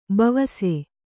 ♪ bavase